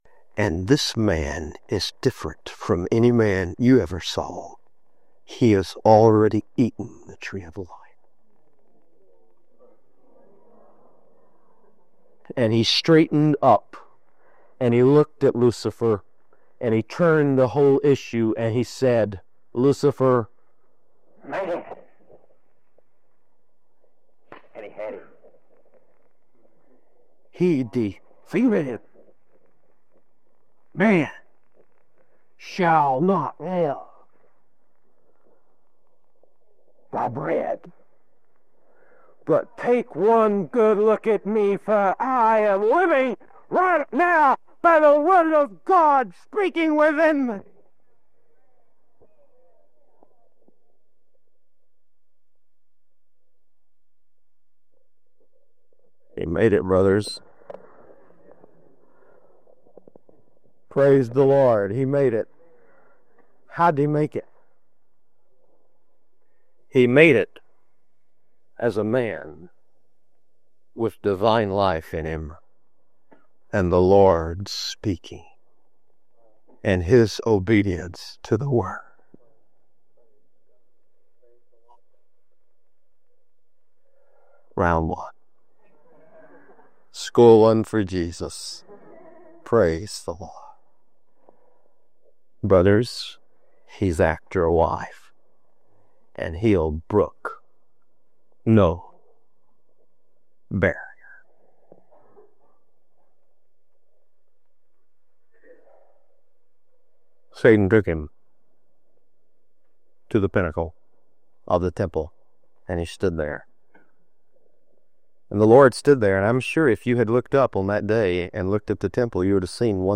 See how Christ defeated Satan, the world, sin, and death to win His Bride. A powerful teaching on divine love and spiritual warfare.